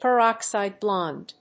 アクセントperóxide blónde
音節peròxide blónde